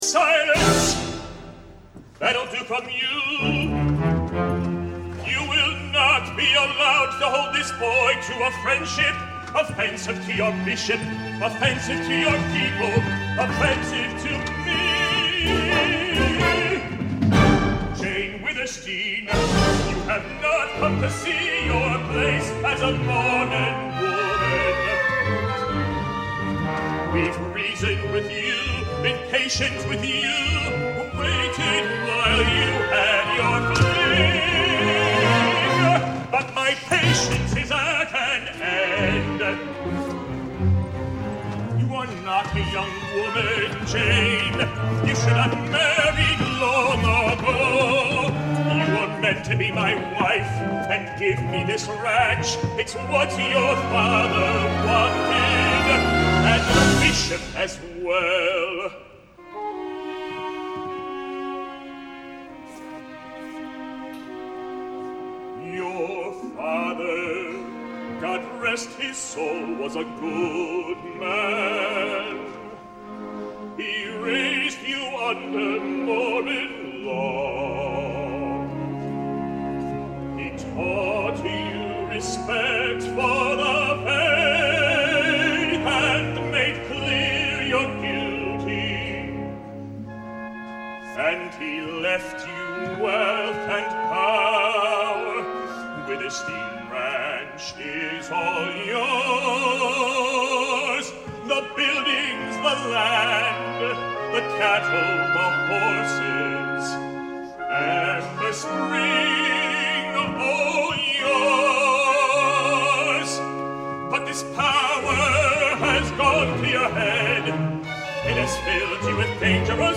Voicing: Baritone Voice and Piano